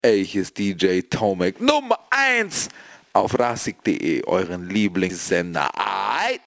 Shout out